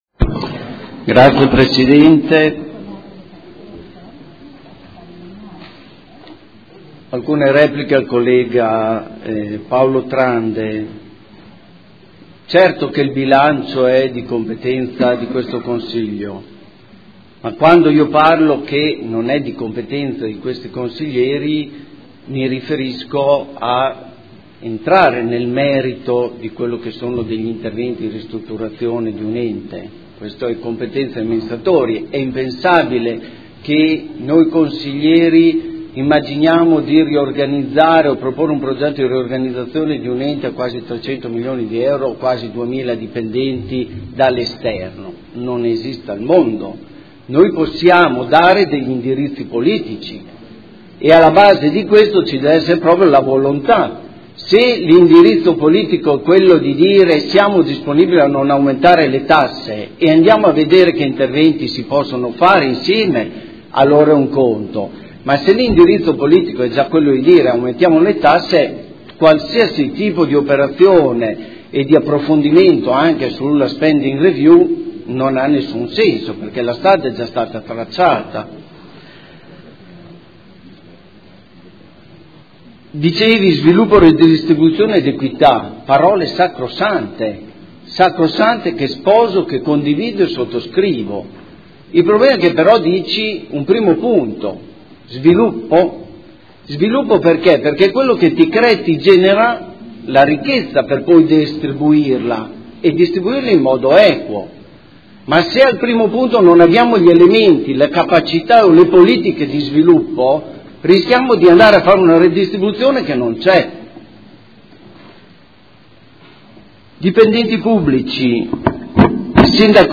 Documento Unico di Programmazione 2015/2019 – Sezione strategica. Dichiarazione di voto